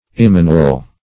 Immanuel \Im*man"u*el\, n. [Heb.